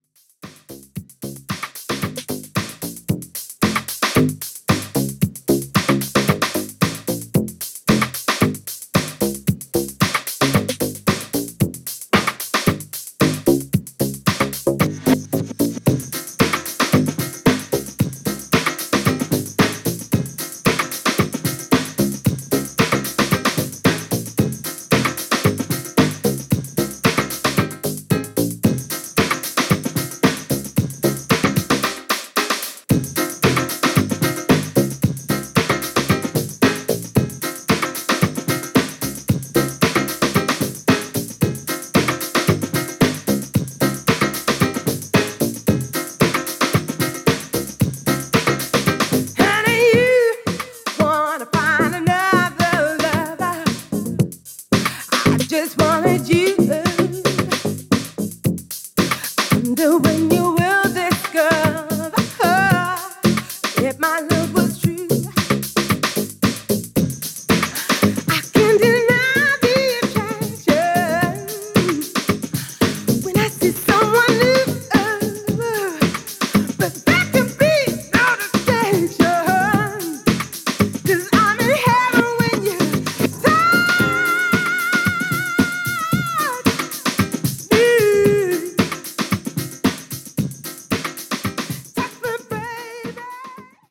この頃のキックは、本当にかっこいいですね！！！